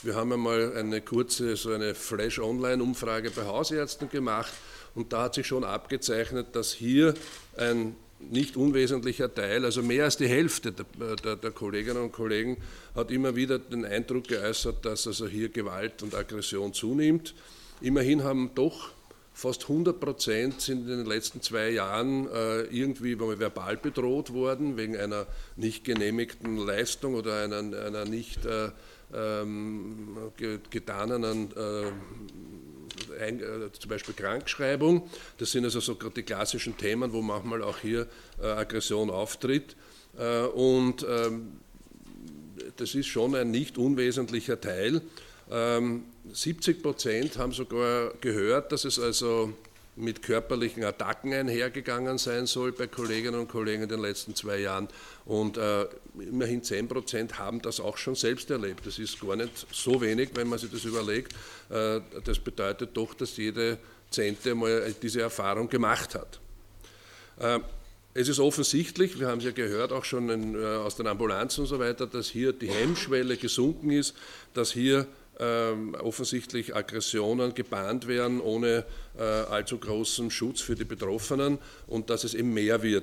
O-Töne (MP3)